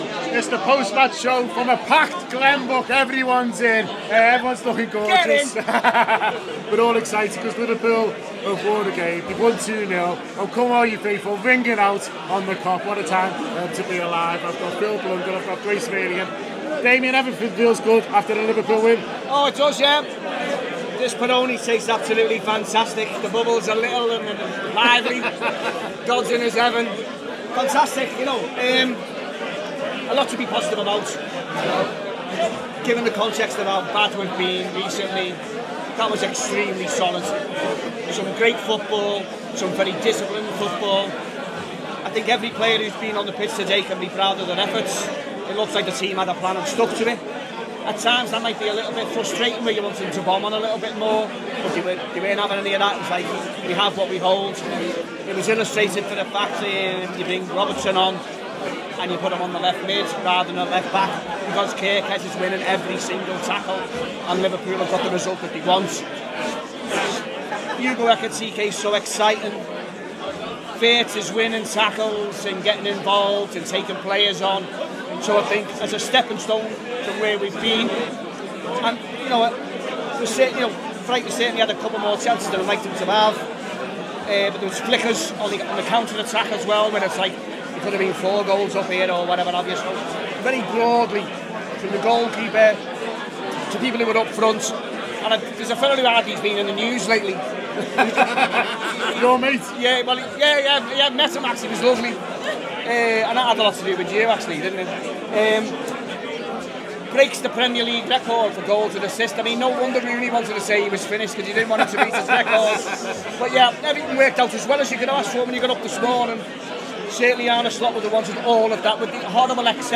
Liverpool 2-0 Brighton: Post Match Show